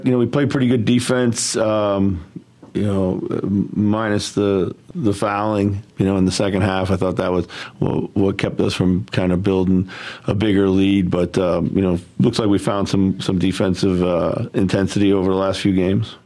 Wolves coach Chris Finch says he likes how his team has come along defensively during this three game stretch.